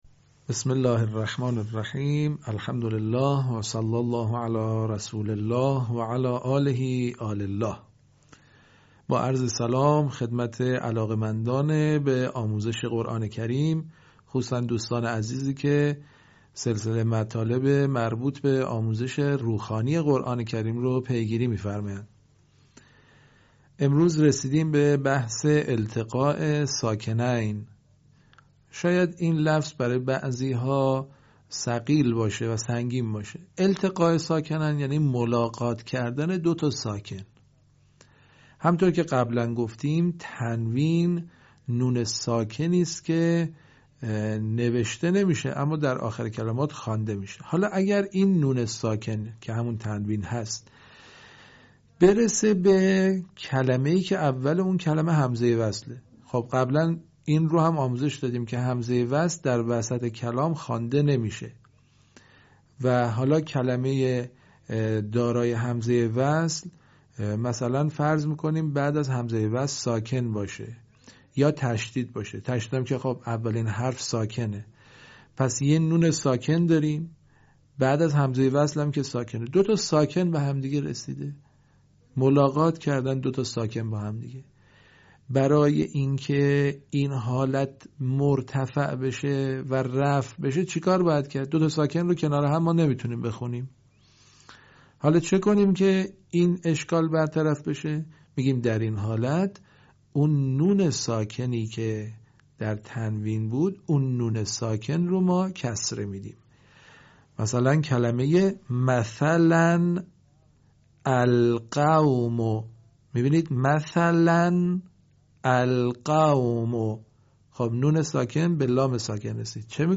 صوت | آموزش التقاع ساکنین در روخوانی قرآن کریم
به همین منظور مجموعه آموزشی شنیداری (صوتی) قرآنی را گردآوری و برای علاقه‌مندان بازنشر می‌کند.